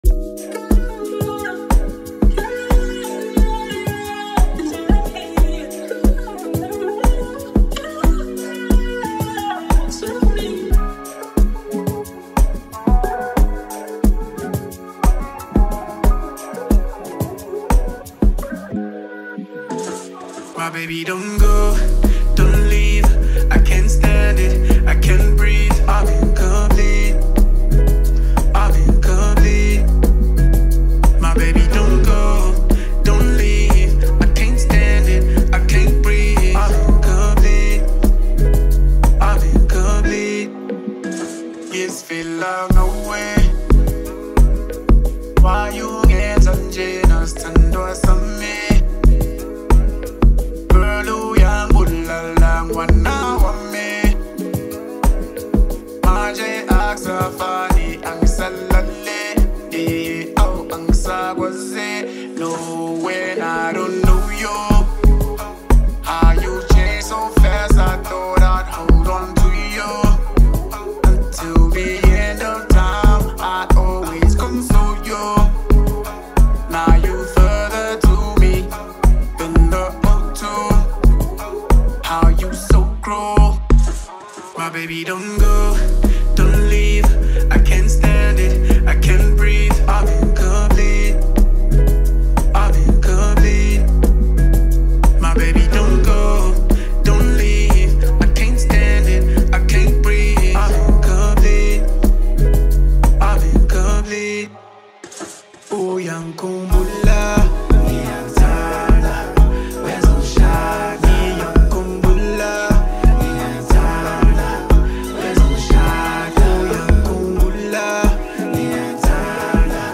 The super talented South African singer-songwriter
smooth vocals